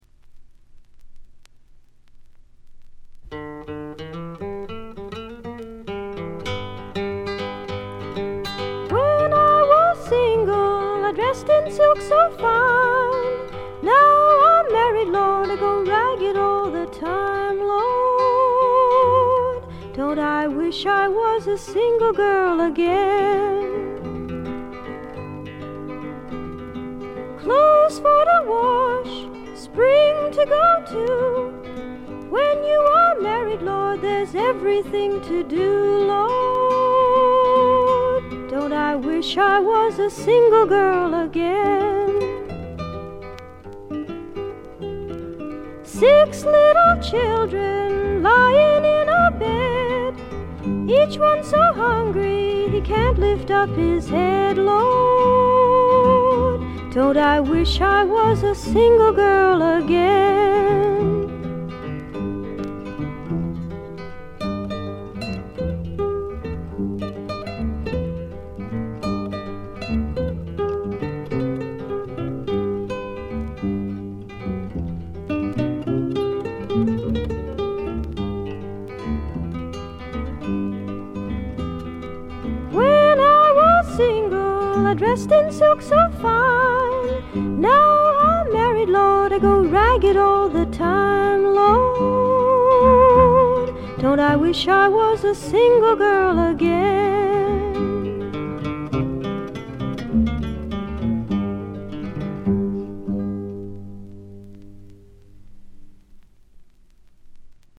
軽微なバックグラウンドノイズやチリプチ。
美しくも素朴な味わいのあるソプラノ・ヴォイスに癒やされてください。
試聴曲は現品からの取り込み音源です。